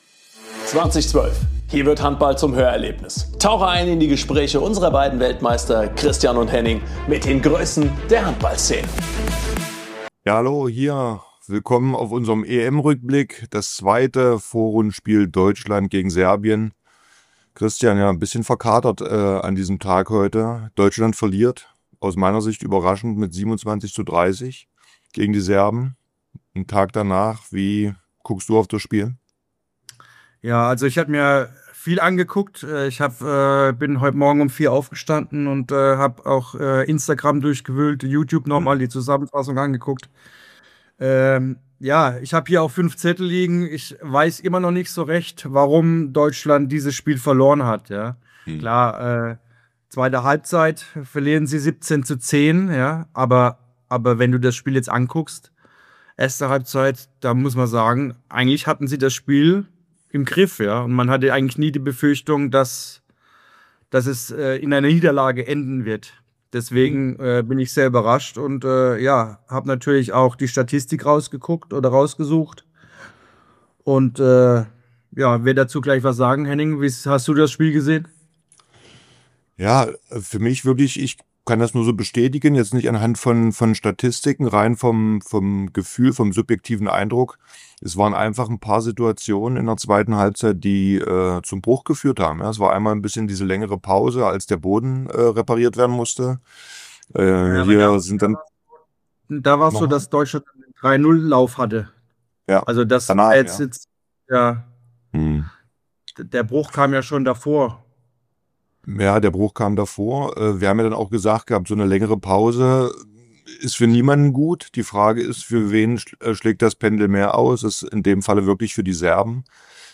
Am Mikrofon: Christian Zeitz & Henning Fritz Zwei Weltmeister.